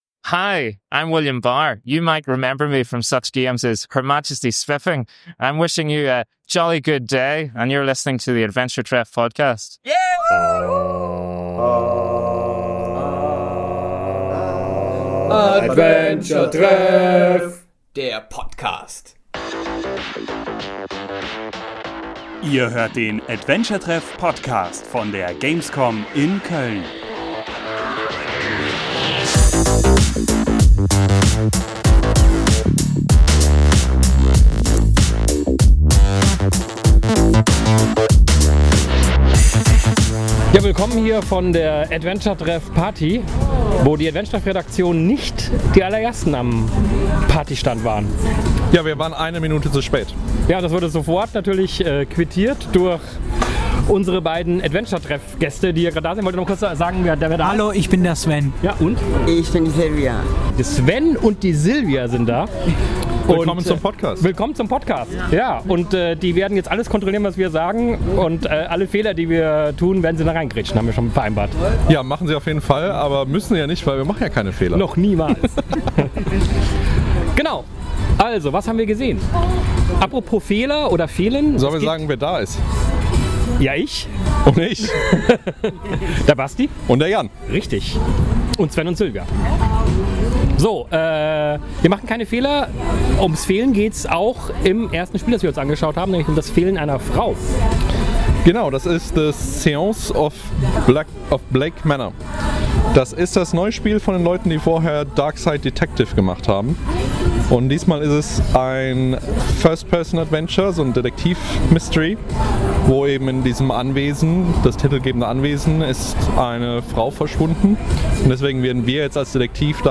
Am Freitag fand unser traditionelles Adventure-Treff-Treffen statt – und ebenso traditionell ist inzwischen auch der Podcast, den wir direkt aus dem Biergarten am Rheinauhafen für euch aufgenommen haben. Darin sprechen wir über die Titel, die wir am gamescom-Freitag in den Hallen noch entdecken konnten, während im Hintergrund nach und nach die Community eintrifft und die Party ihren Lauf nimmt.